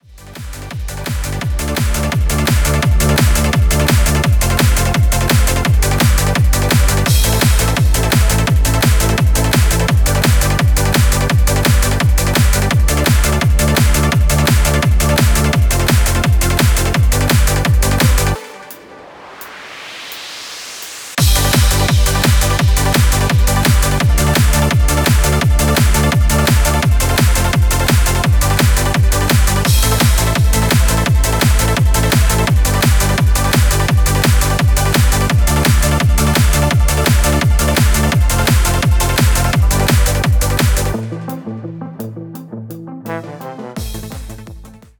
ohne Backing Vocals
Die besten Playbacks Instrumentals und Karaoke Versionen .